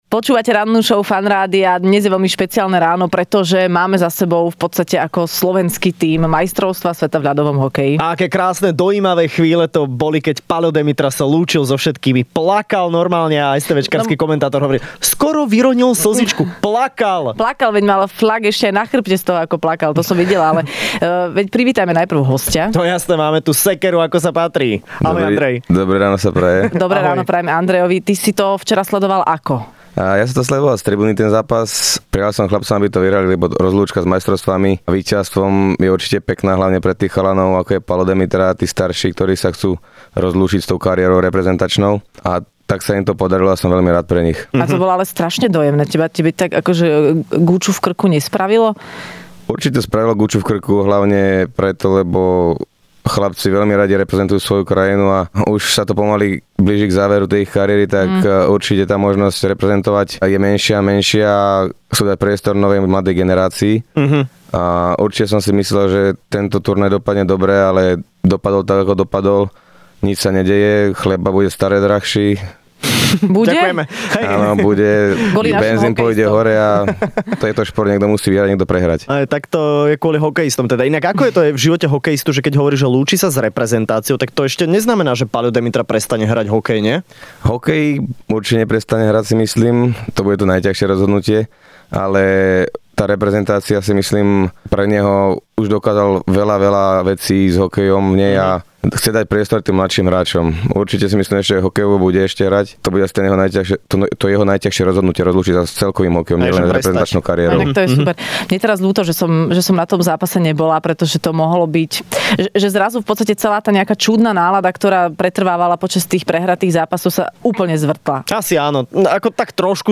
Hosťom v Rannej šou bol hokejový obranca, jeden z najlepších v NHL, Andrej Sekera